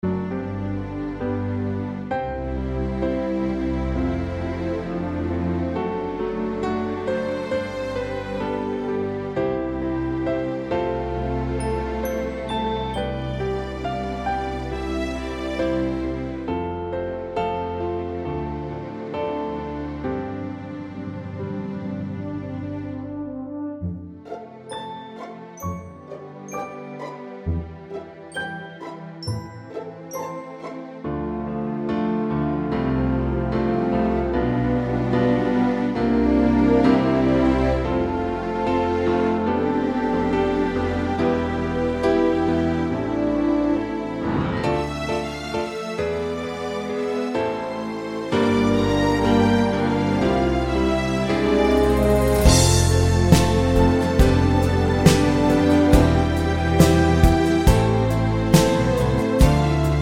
no Backing Vocals Soundtracks 4:23 Buy £1.50